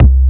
45 BASS 1 -R.wav